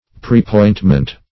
Preappointment \Pre`ap*point"ment\, n. Previous appointment.